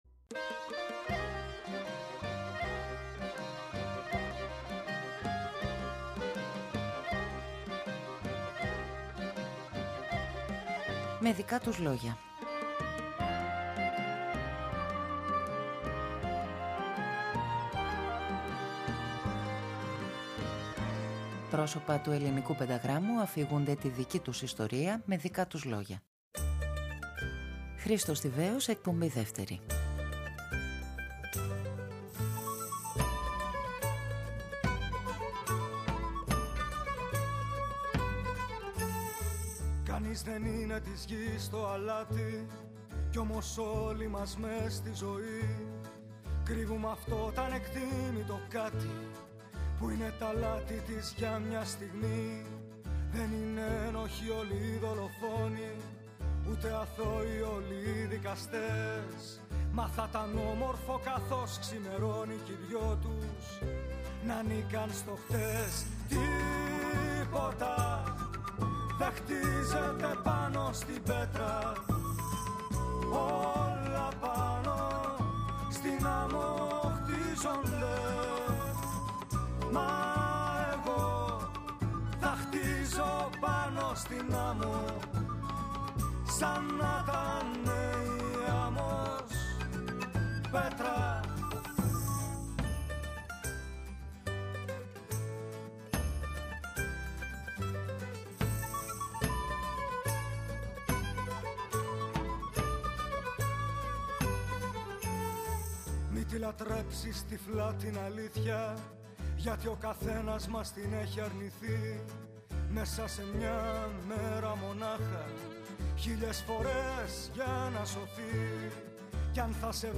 Πρόσωπα του πενταγράμμου αφηγούνται τη δική τους ιστορία…
Οι “Συνήθεις Ύποπτοι” και η διάλυσή τους,η γνωριμία με την Αρλέτα, οι συνεργασίες με τον Βασίλη Δημητρίου, τον Γιώργο Ανδρέου, τον Νικόλα Πιοβάνι. Ο Χρήστος Θηβαίος αφηγείται τη δική του ιστορία με δικά του λόγια.